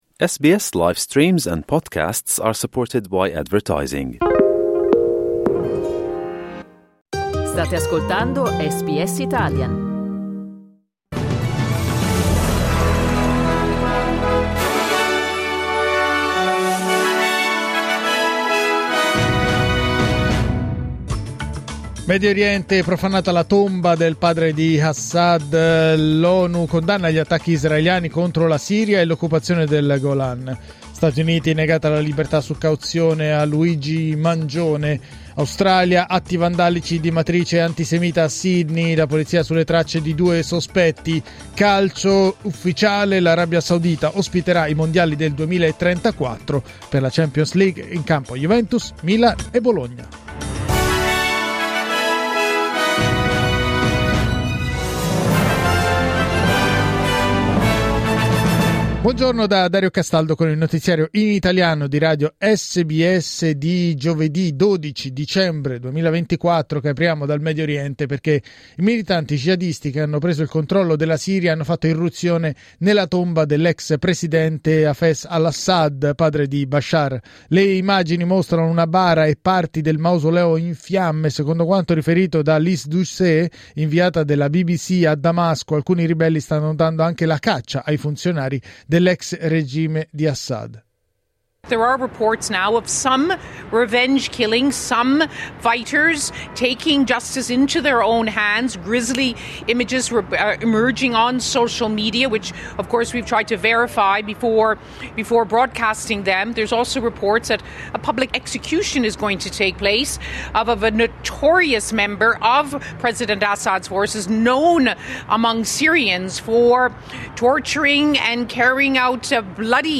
1 Giornale radio giovedì 12 dicembre 2024 12:38